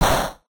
Battle damage weak.ogg